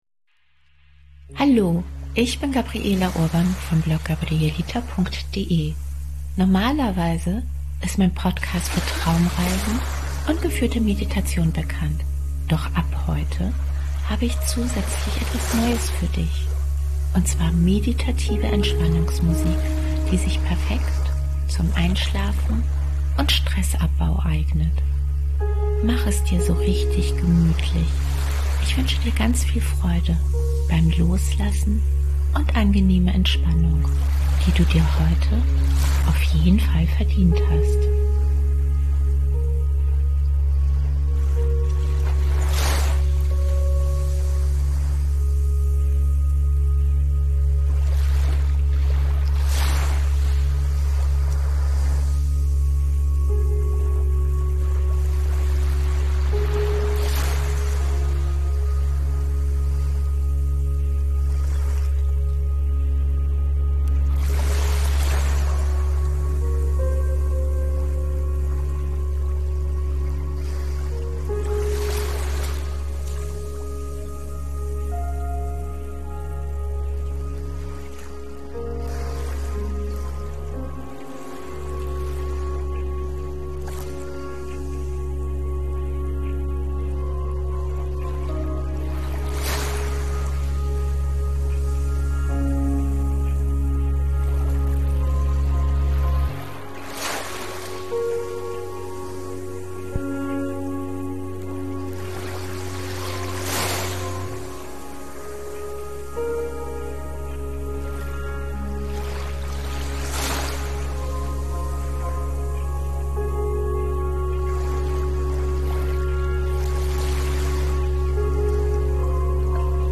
meditative Entspannungsmusik
Entspannungsmusik mit Naturgeräuschen zum Einschlafen & Stressabbau